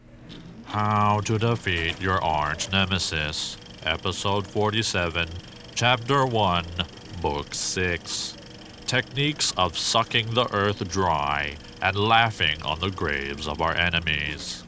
Nasally, Comic, Monotone